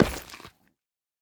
Minecraft Version Minecraft Version latest Latest Release | Latest Snapshot latest / assets / minecraft / sounds / block / nether_ore / step1.ogg Compare With Compare With Latest Release | Latest Snapshot